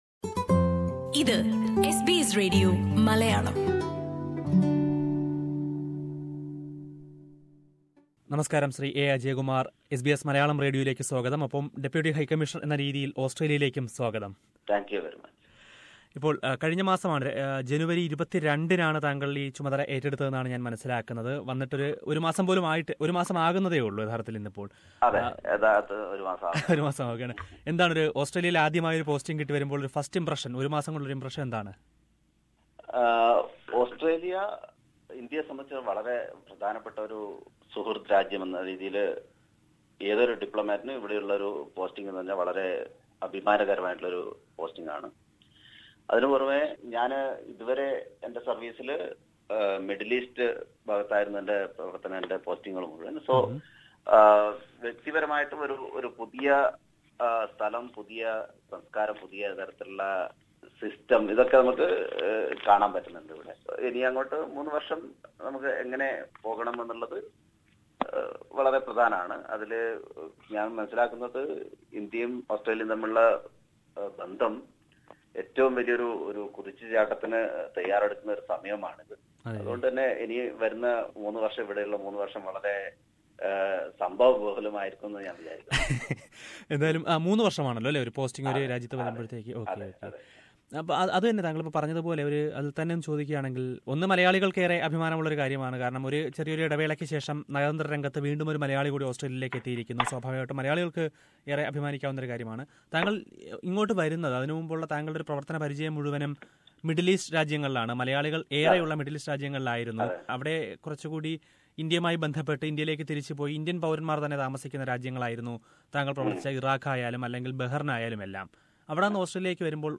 Exclusive interview with new Deputy High Commissioner A Ajay Kumar